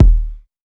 • 2000s Reverb Kickdrum Sample D# Key 22.wav
Royality free kick one shot tuned to the D# note. Loudest frequency: 109Hz